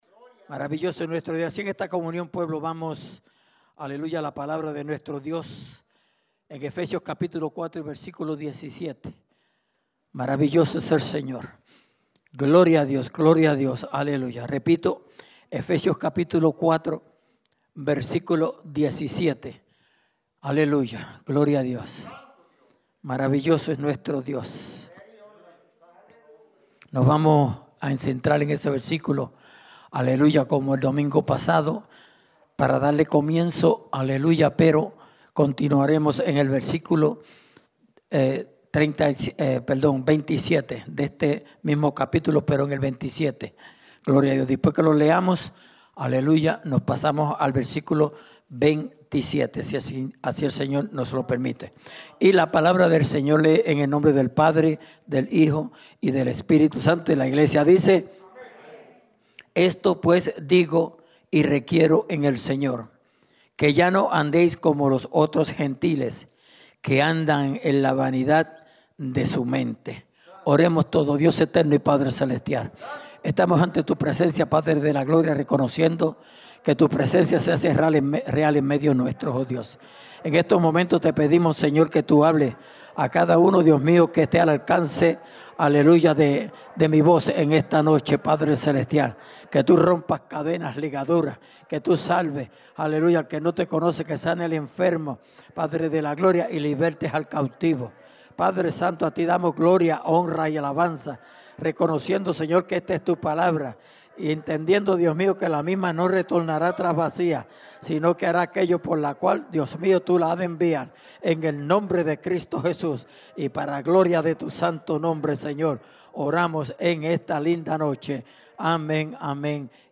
Mensaje
grabado el 11/22/2020 en la Iglesia Misión Evangélica en Souderton, PA